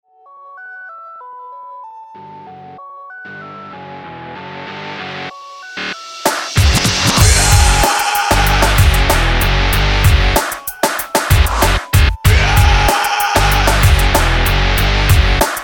Tá prvá ukážka - to bude asi poriadne orezaný noise gate.
1.ukazka je studiova praca. Takto nasekane gitary su dnes velka moda a uplne najjednoduchsie sa to robi v SW editore.